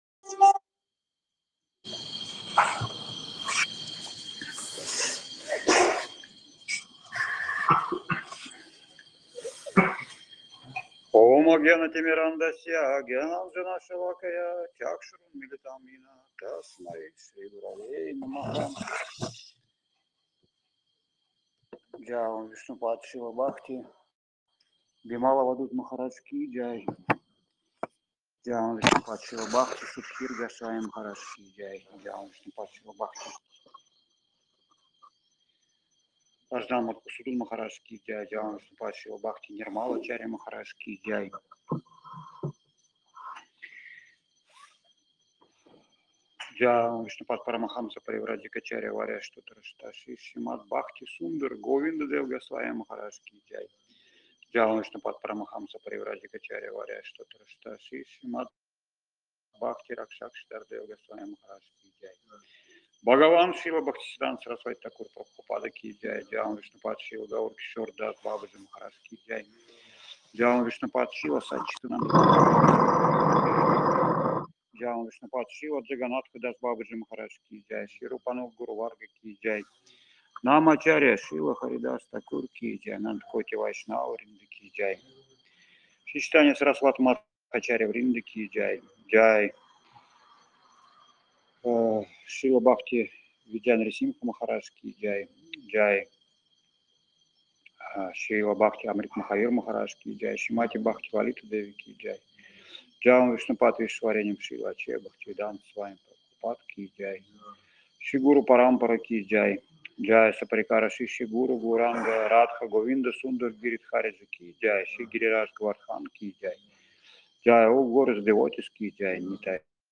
Лекции полностью
Бхаджан
Киртан